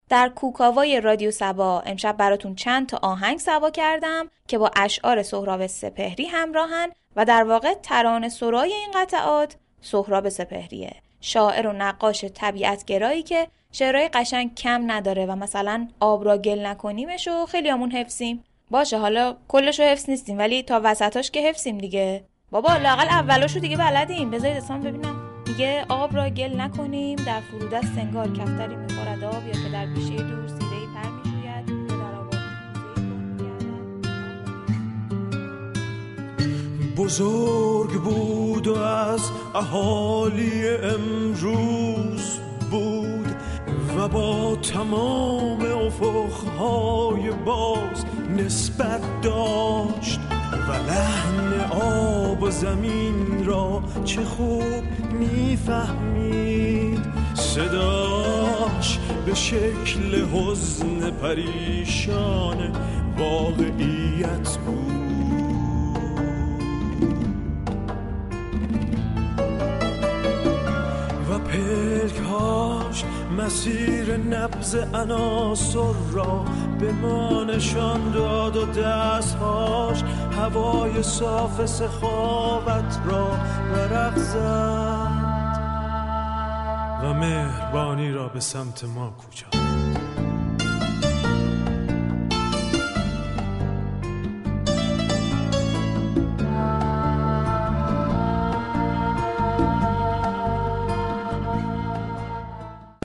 با تركیب متن، ترانه و موسیقی بسته شنیداری